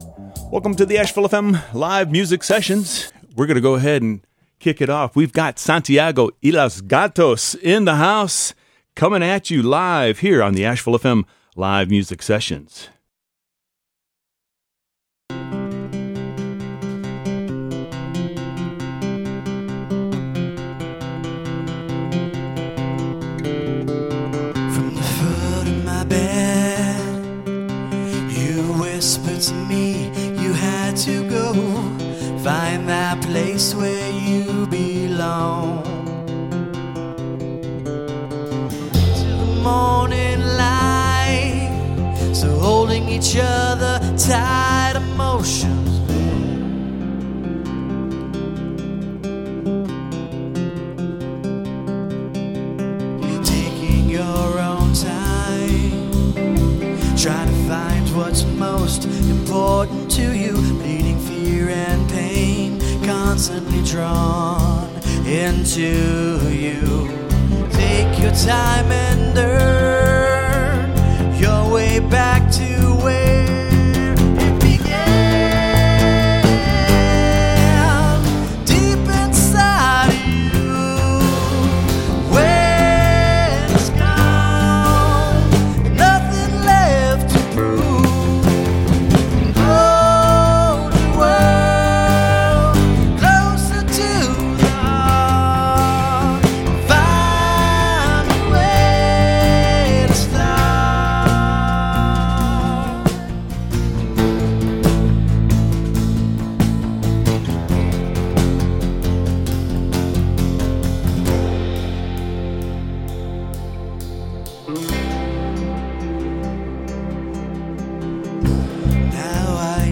Recorded live from the 103.3 AshevilleFM studio.